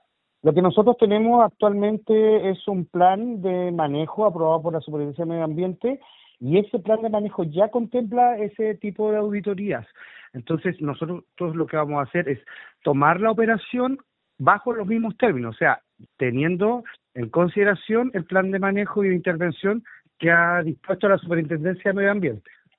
Considerando que la Asociación ya no podrá fiscalizar el cumplimiento de la normativa ambiental pues serán los operarios del lugar, el alcalde Silva explicó la figura legal que usarán para vigilar aquello.